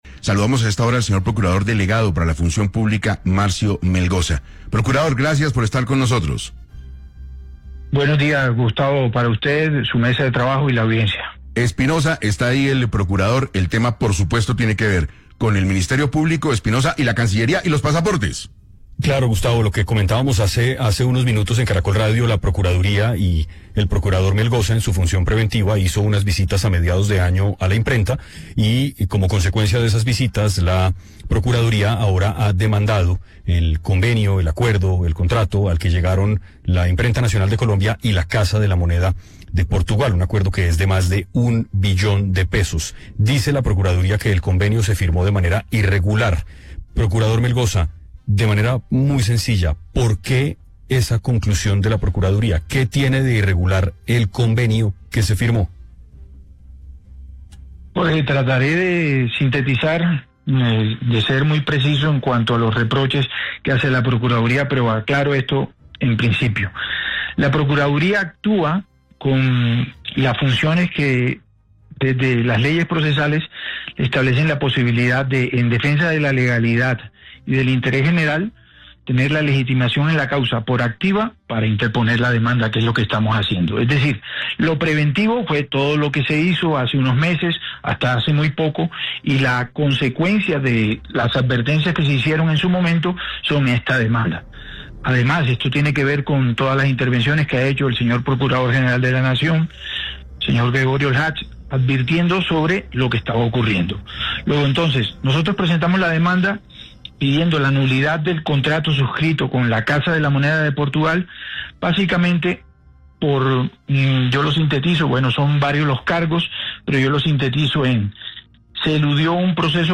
Así lo afirmó para 6AM de Caracol Radio el procurador delegado para la Función Pública, Marcio Melgosa.